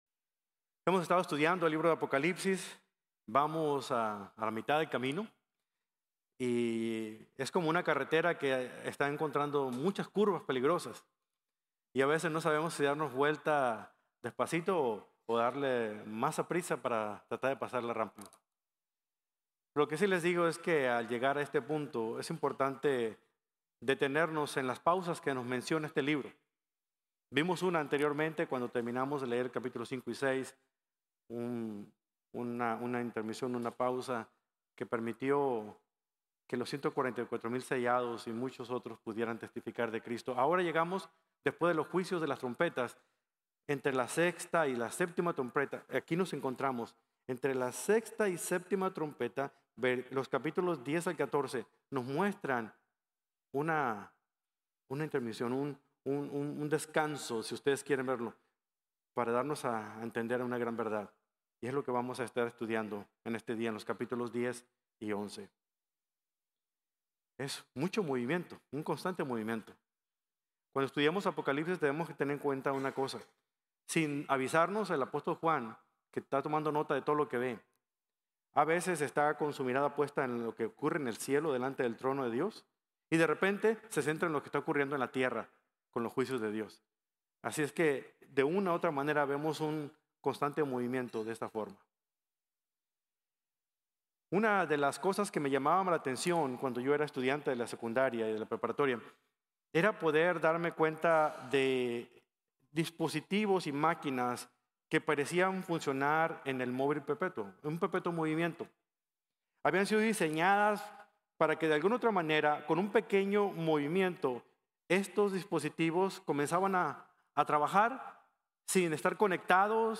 La Gloria Imparable | Sermon | Grace Bible Church